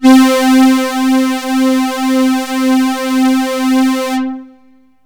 STRINGS 0008.wav